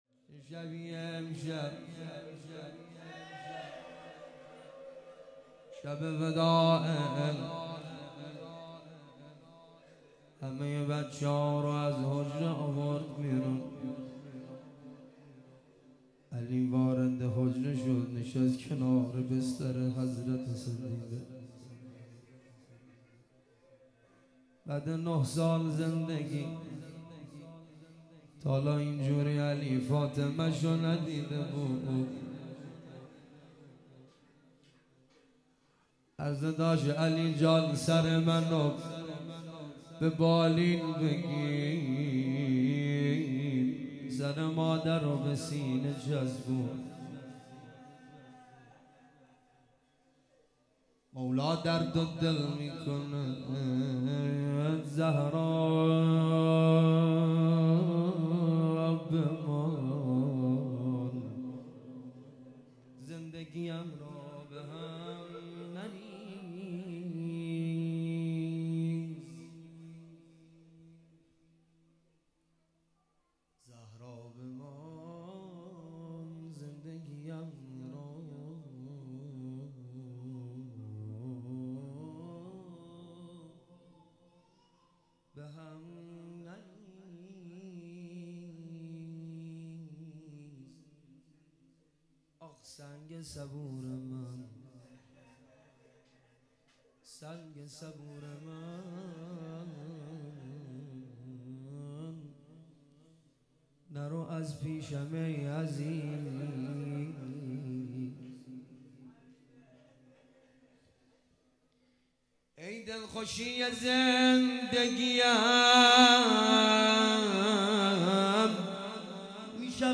روضه شب شهادت حضرت زهرا (س)
روضه خوانی